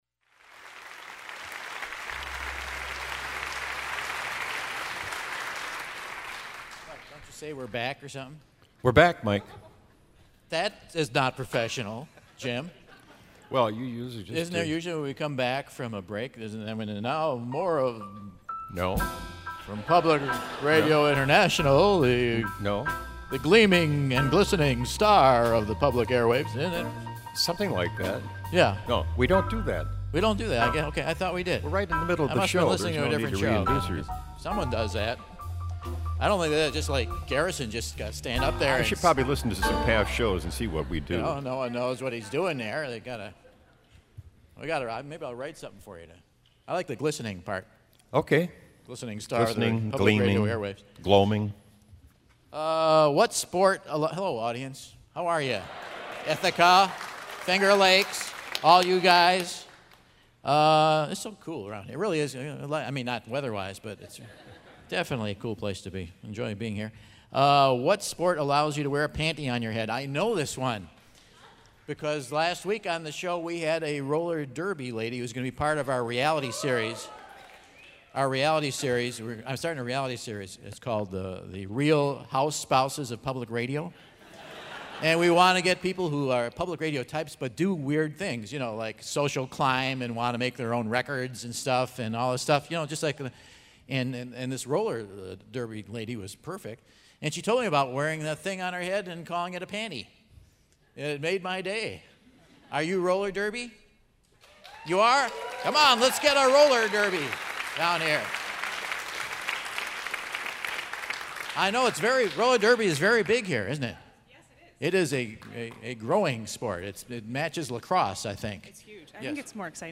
Quiz B - June 16, 2012 in Ithaca, NY